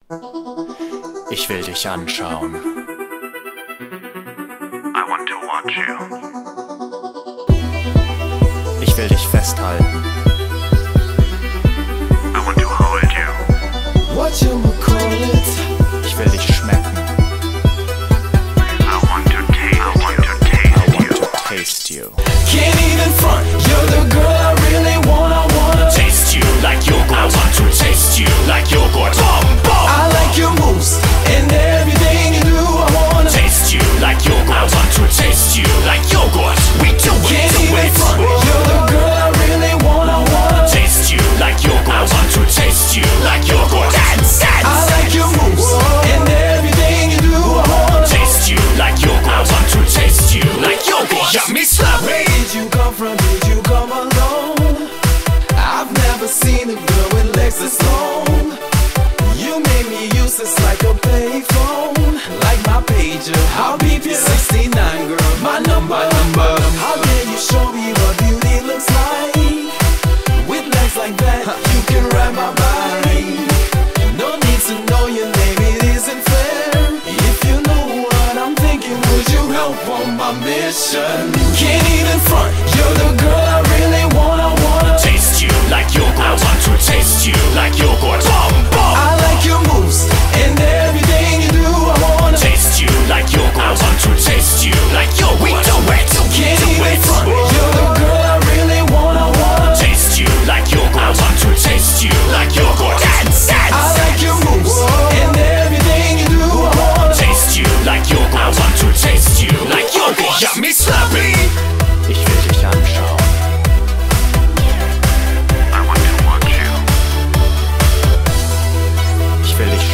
BPM130
Audio QualityPerfect (High Quality)
The insert and closing song for Season 1 Episode 4